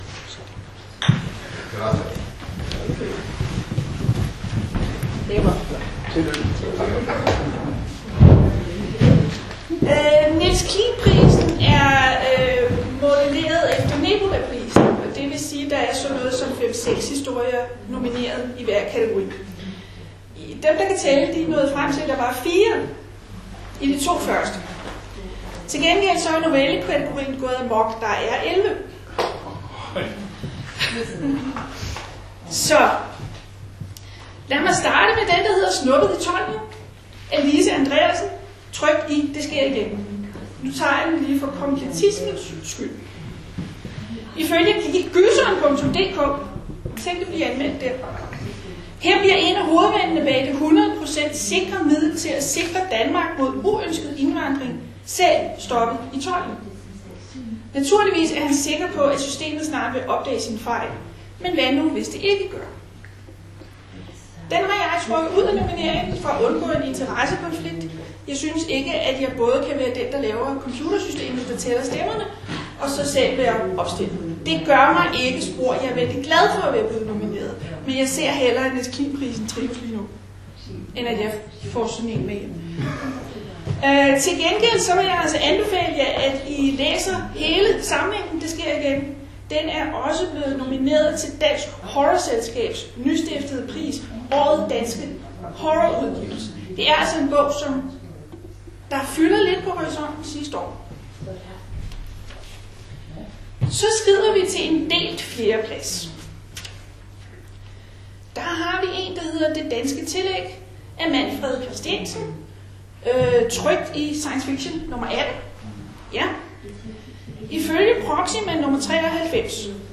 Teksten er med, i form af de noter jeg fulgte undervejs, sådan i tilfælde af, at der er et par ord undervejs, der er utydelige.